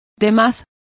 Complete with pronunciation of the translation of other.